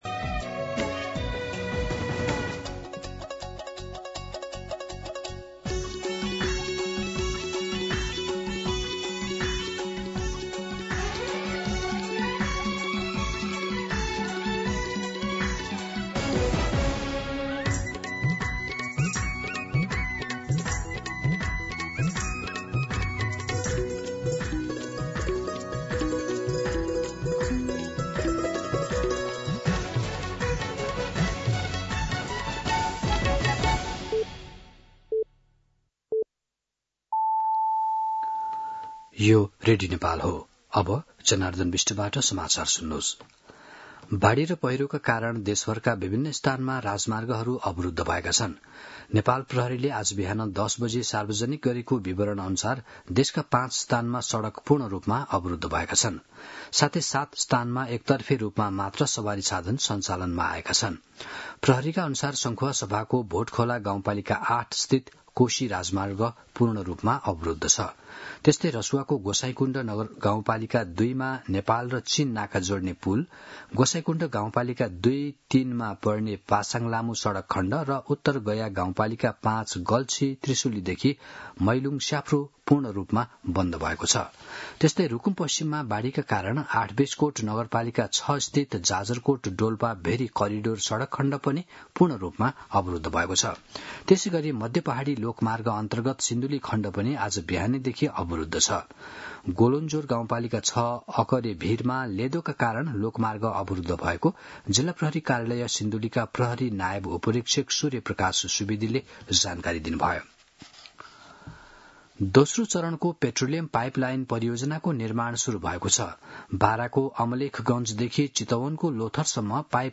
दिउँसो १ बजेको नेपाली समाचार : १७ साउन , २०८२
1-pm-Nepali-News-1.mp3